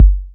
Waka KICK Edited (50).wav